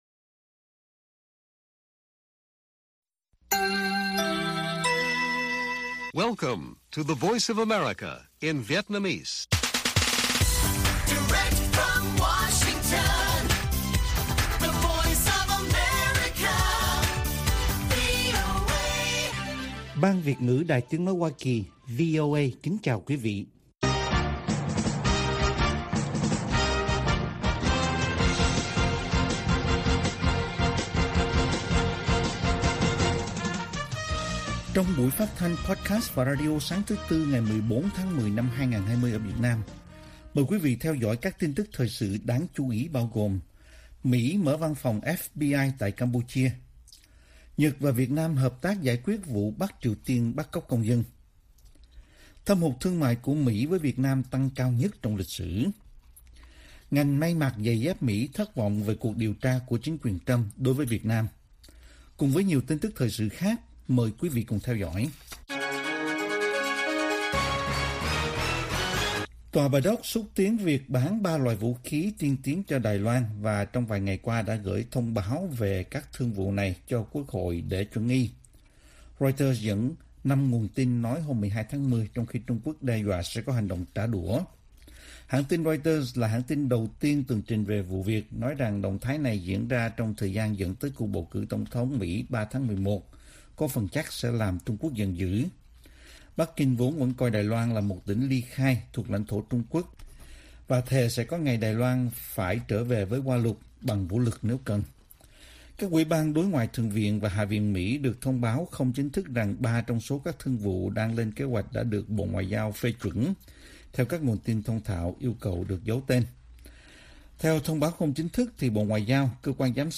Bản tin VOA ngày 14/10/2020